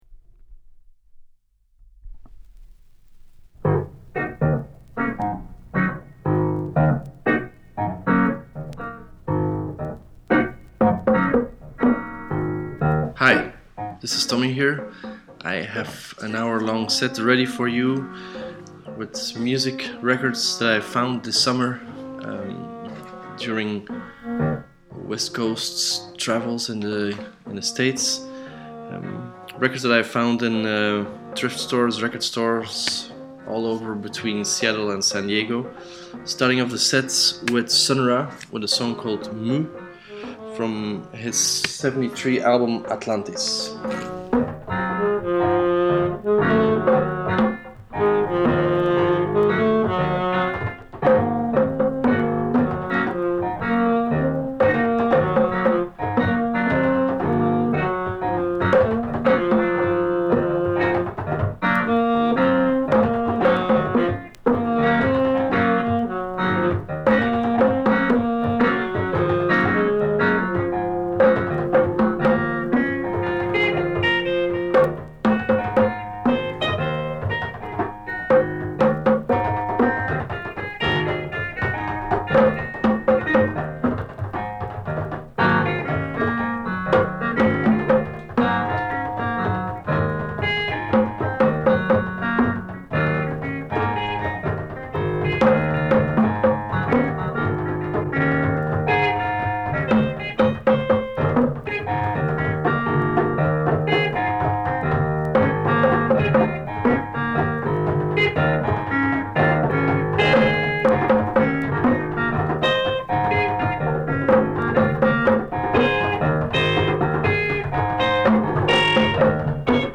Avant-Garde Electronic International Psych